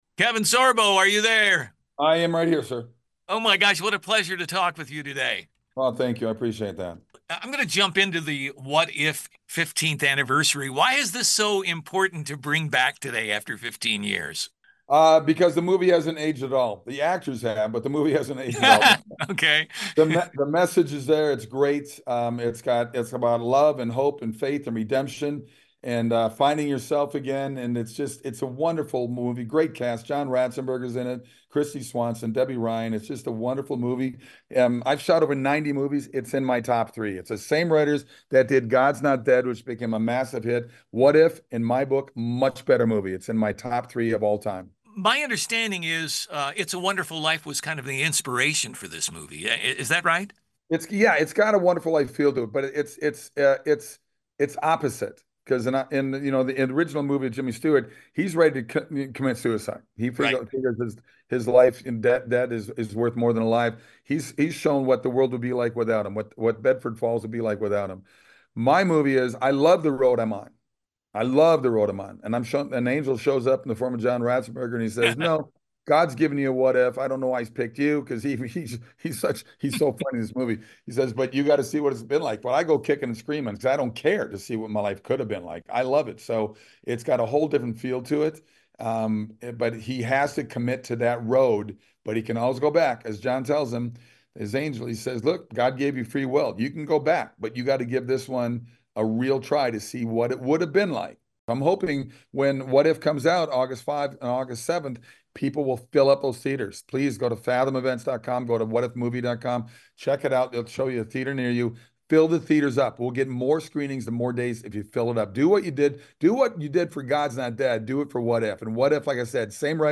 KEVIN SORBO – Talks His Movie, “What If”.  Radio Edit version.